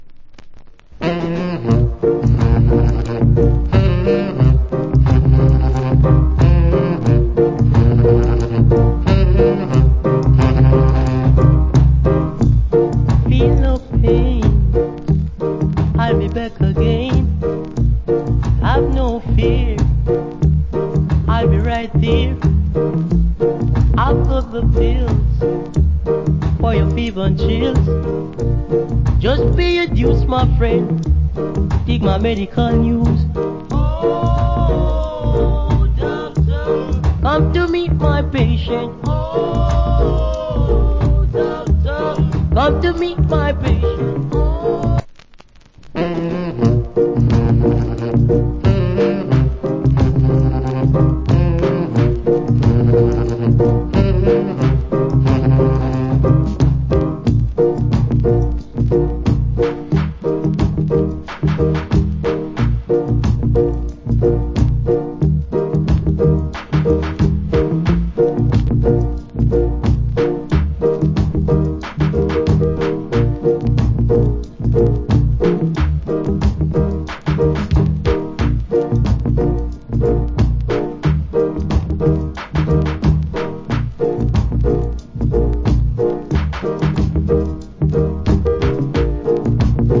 Foundation Rock Steady Vocal.